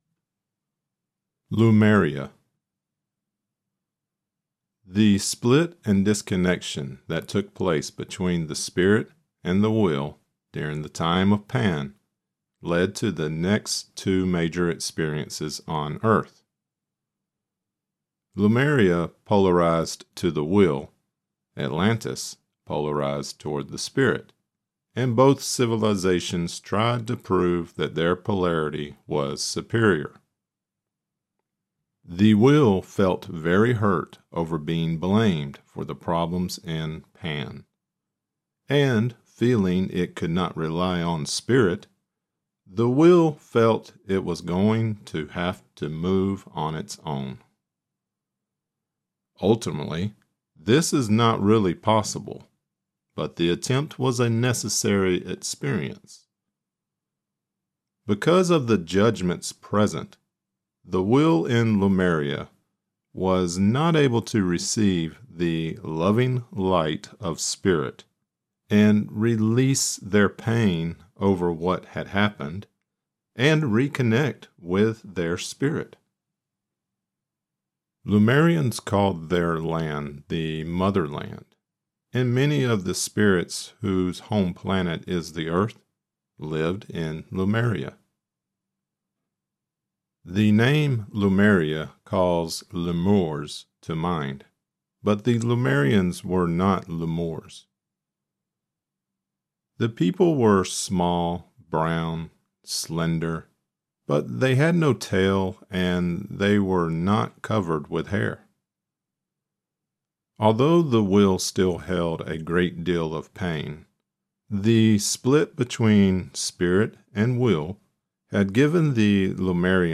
Lemuria (audio only)